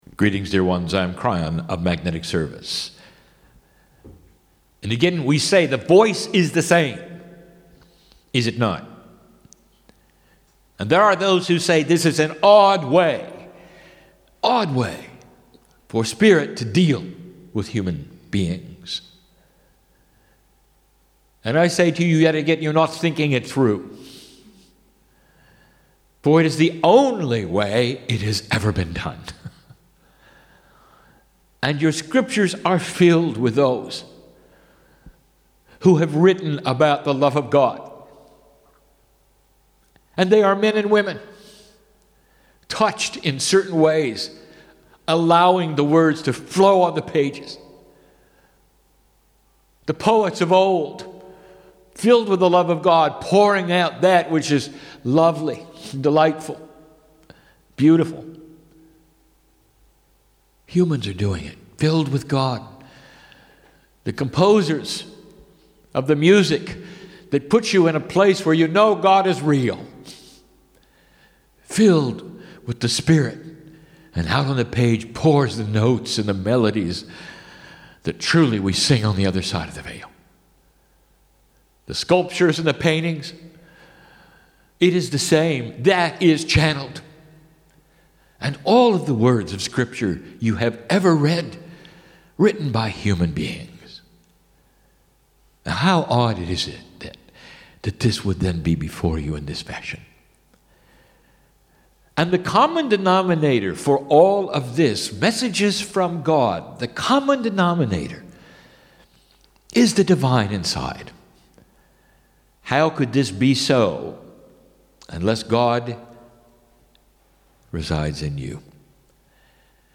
Perth, WA, Australia
Sunday "Mini Channelling" 8:19 min